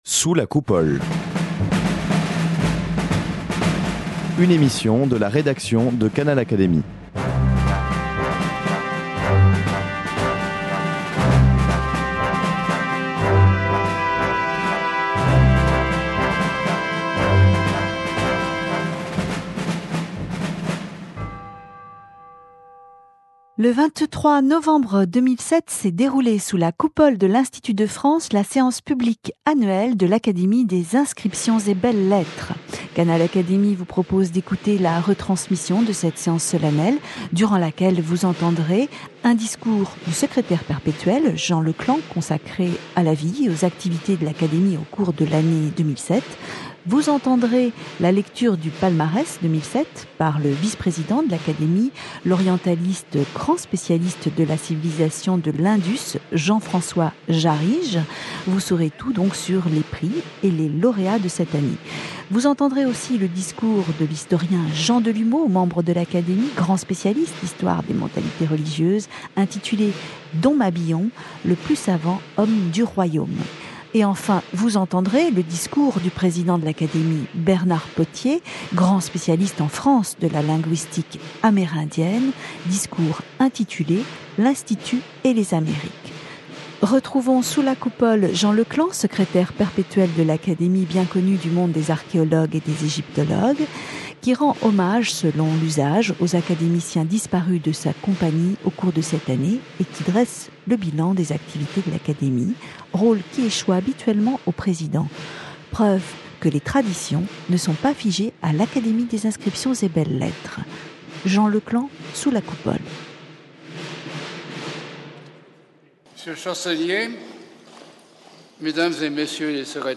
Le 23 novembre 2007, s’est déroulée sous la Coupole de l’Institut de France, la séance publique annuelle de l’Académie des inscriptions et belles-lettres. Canal Académie vous propose d’écouter la retransmission de cette séance solennelle. Vous saurez tout des activités de cette compagnie, du Palmarès 2007, des lauréats choisis cette année par les académiciens, de l’actualité de Jean Mabillon autour duquel, l’Académie organise un colloque dans le cadre du tricentenaire de sa disparition et enfin, des liens entre l’Institut et le beau sujet « des Amériques ».